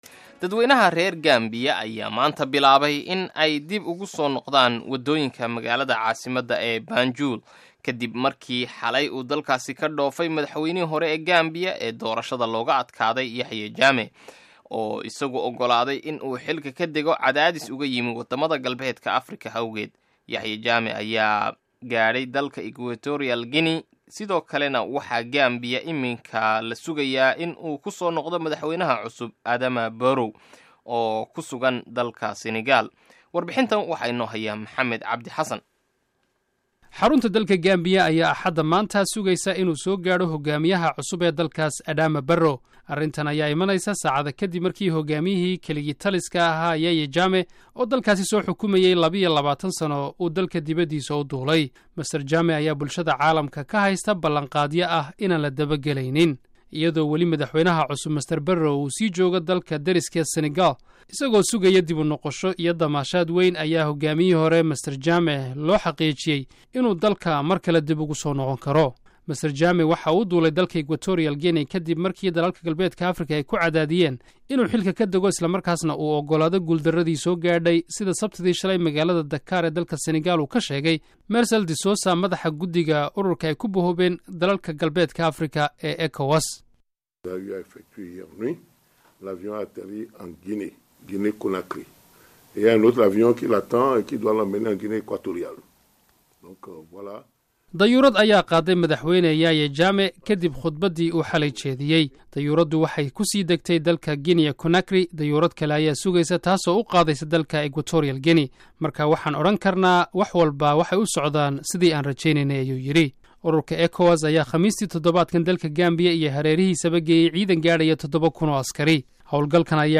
Dhageyso warbixinta Gambia